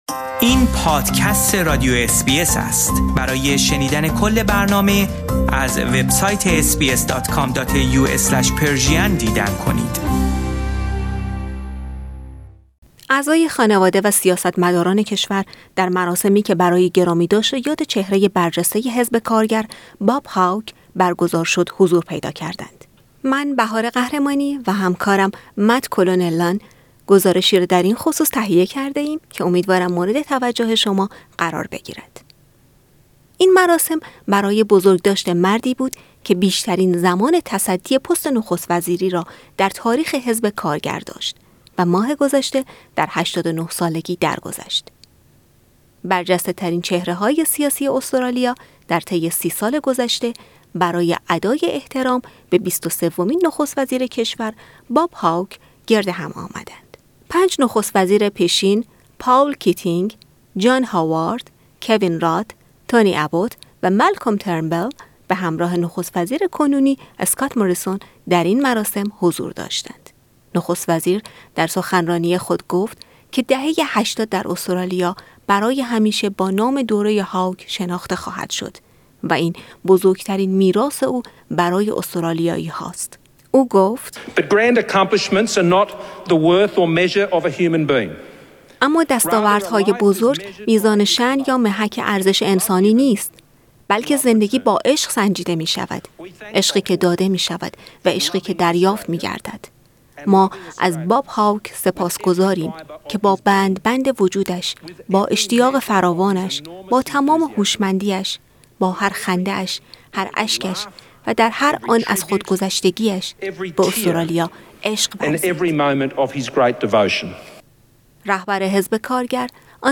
Bob Hawke has been farewelled at a state memorial service in Sydney. Source: AAP